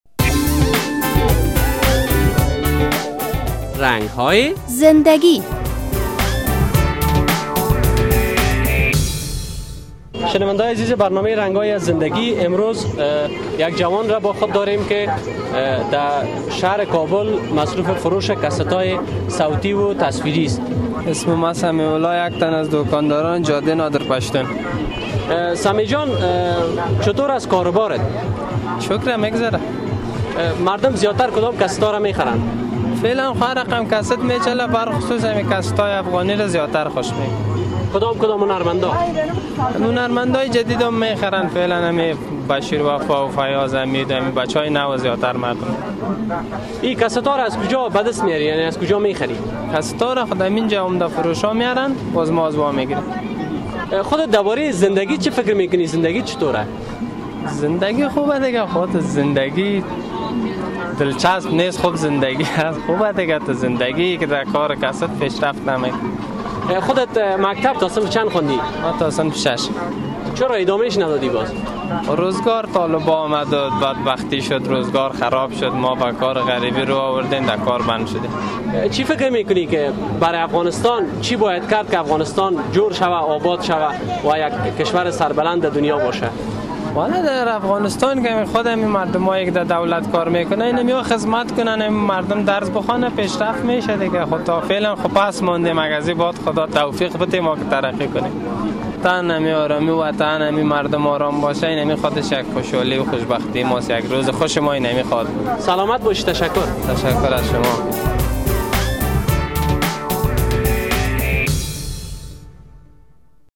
در مصاحبه با خبرنگار رادیو آزادی در مورد کار و بارش قصه کرده است.